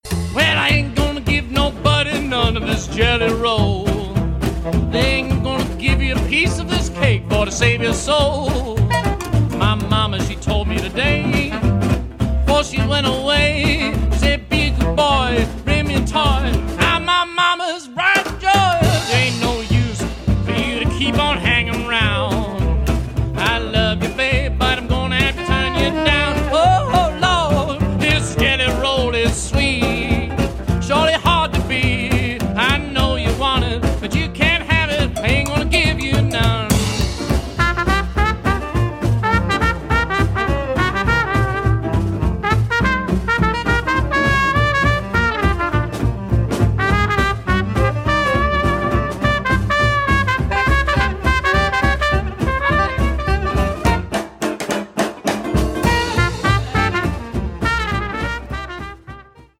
swing décontracté
trompette
saxophone soprano, clarinette
guitare, chant
contrebasse
batterie